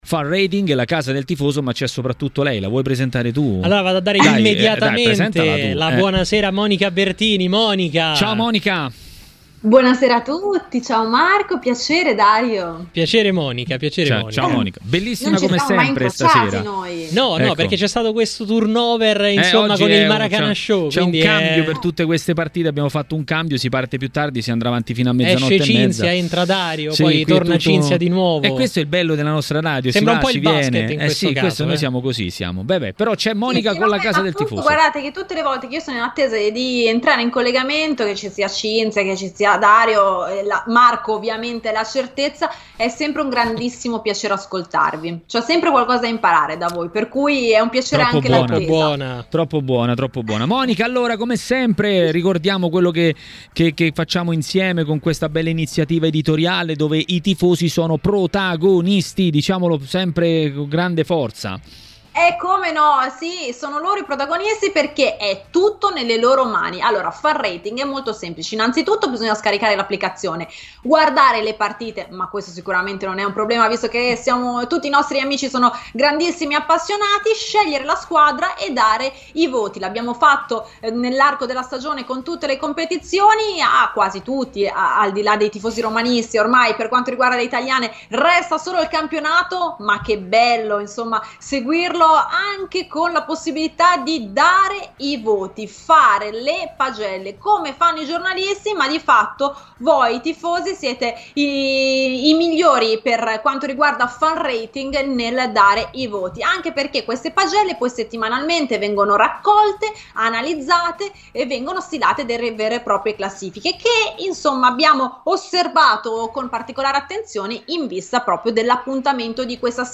A TMW Radio, durante il Maracanà Show, nuovo appuntamento con Fan Rating, app collegata al format "La Casa del Tifoso".
Ospite della serata l'ex calciatore Tomas Locatelli.